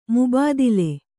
♪ mubādile